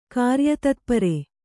♪ kāryatatpare